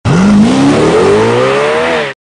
ad_car_jia_su.MP3